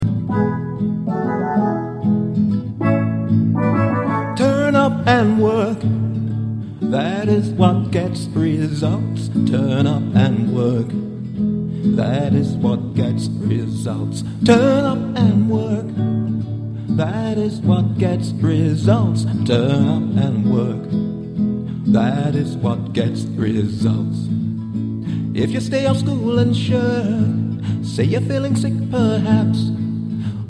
Listen to the vocal track.